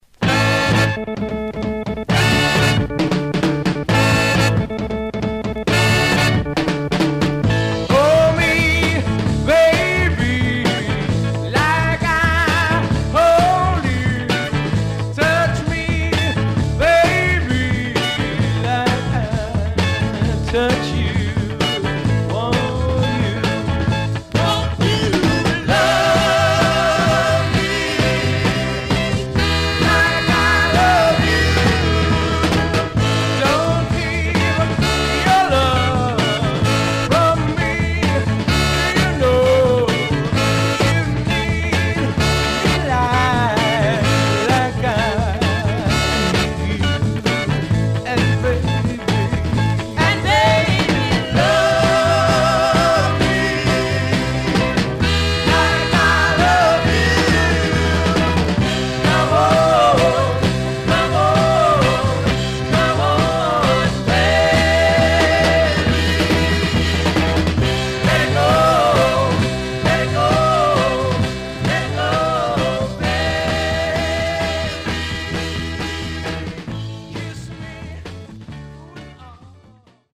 Surface noise/wear Stereo/mono Mono
Soul